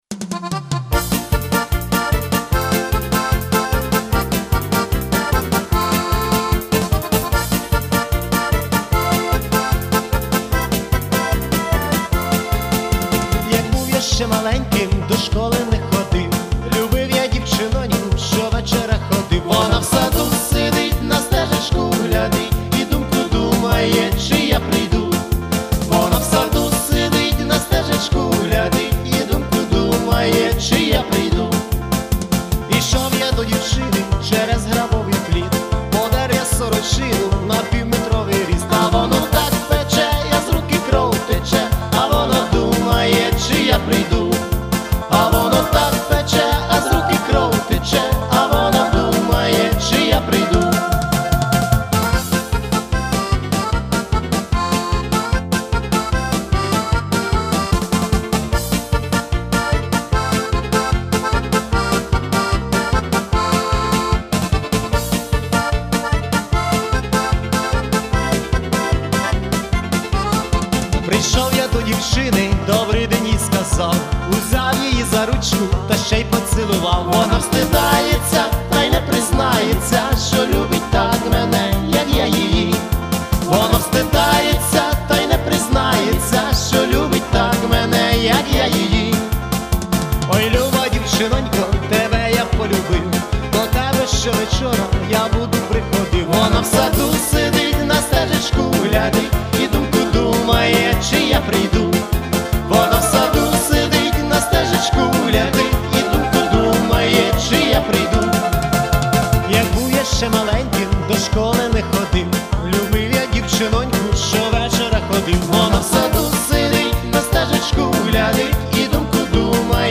Всі мінусовки жанру Народні UA
Плюсовий запис